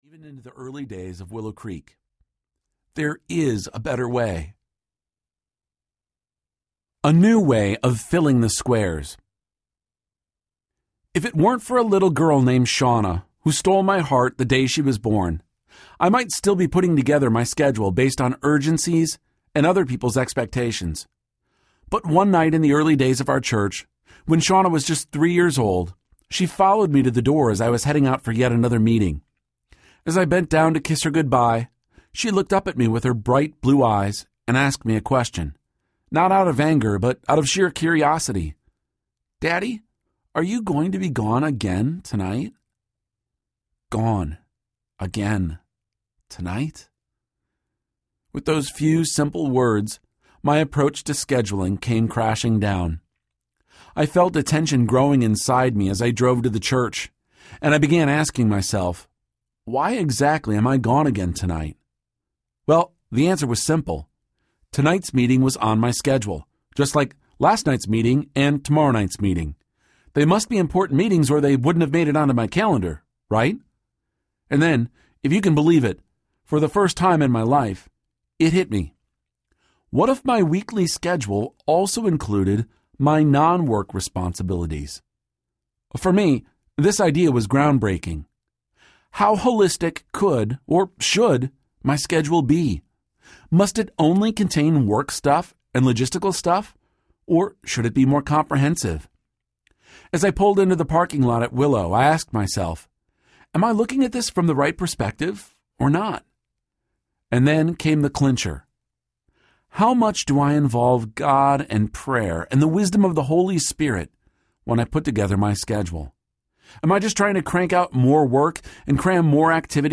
Simplify Audiobook
Narrator
9.75 Hrs. – Unabridged